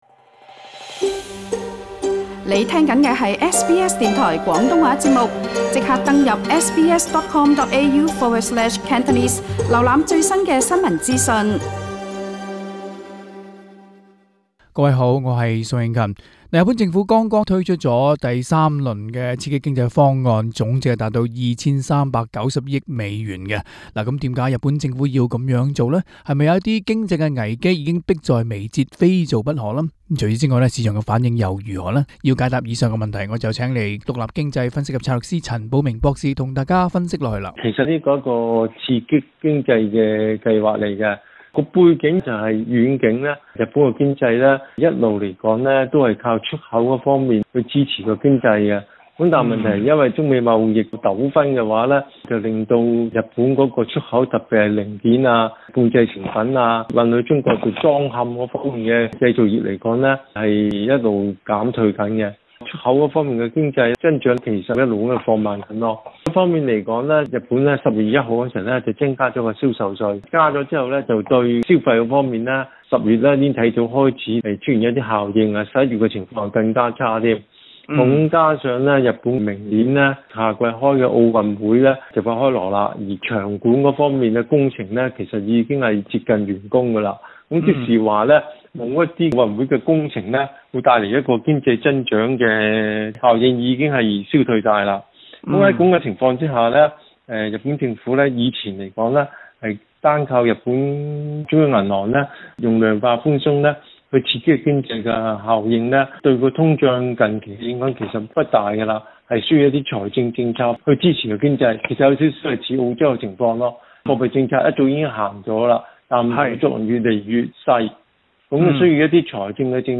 AAP Source: AAP SBS廣東話節目 View Podcast Series Follow and Subscribe Apple Podcasts YouTube Spotify Download (14.74MB) Download the SBS Audio app Available on iOS and Android 今期【寰宇金融】跟大家討論日本政府推出第三輪的刺激經濟方案。